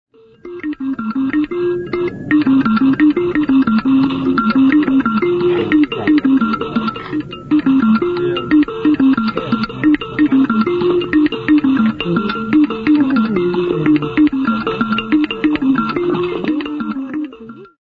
TR217-09.mp3 of Song with dingo mbira